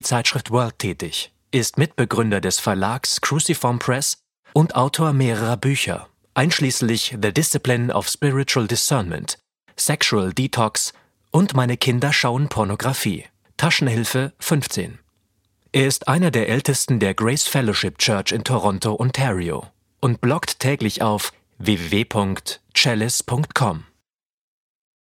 • Sachgebiet: Hörbücher